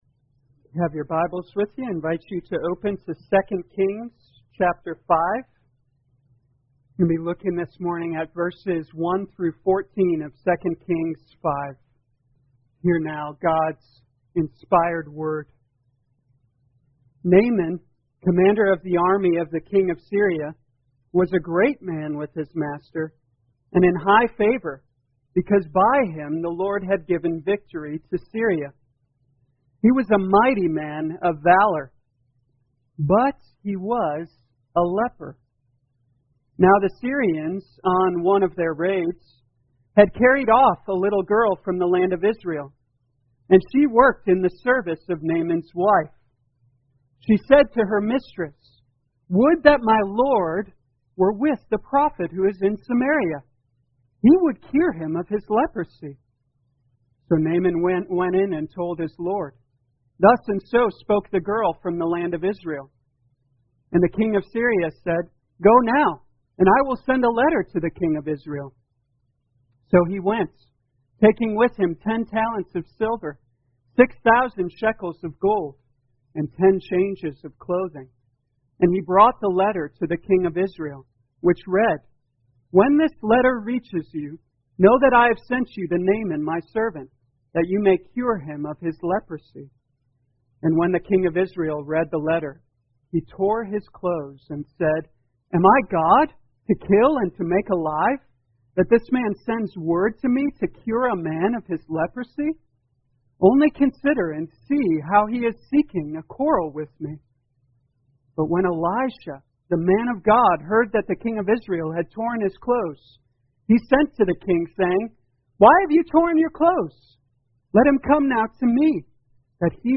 2020 2 Kings Humility Morning Service Download